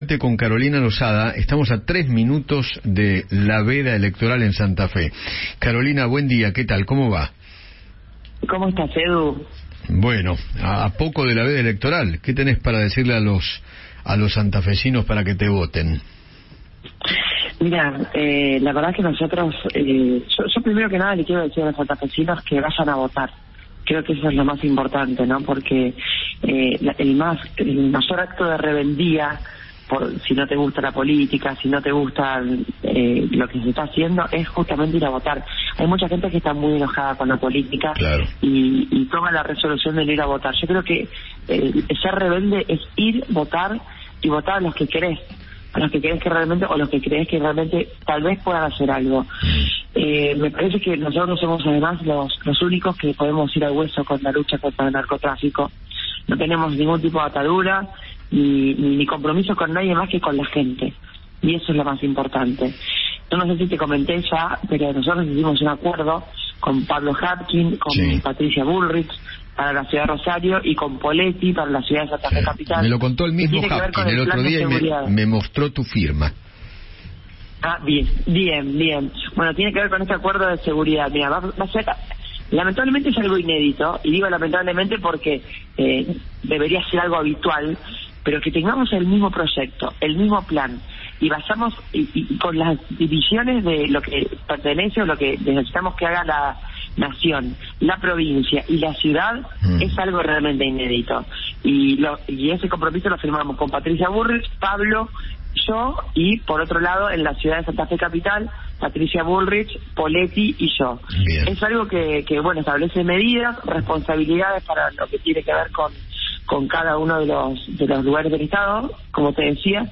Carolina Losada, precandidata a gobernadora por Unidos para Cambiar Santa Fe, habló con Eduardo Feinmann sobre las elecciones en la provincia, minutos antes Del inicio de la veda electoral.